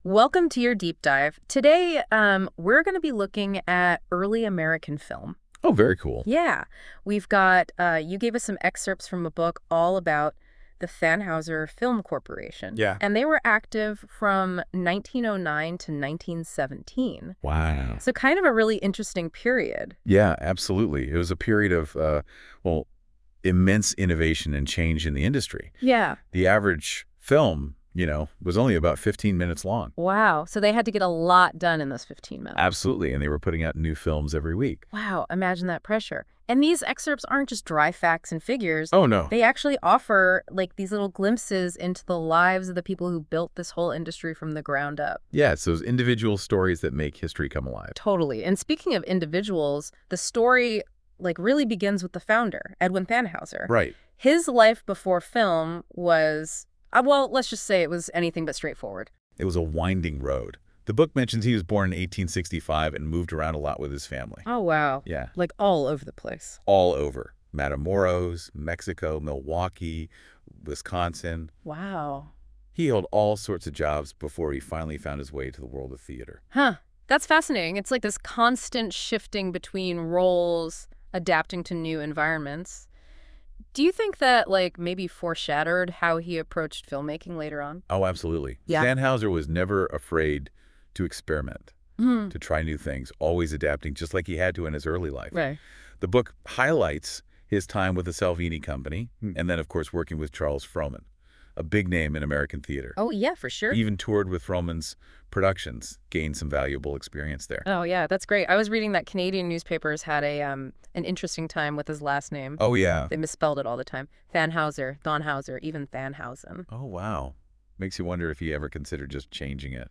Listen to a 19 minute AI generated "podcast" on the history of the studio.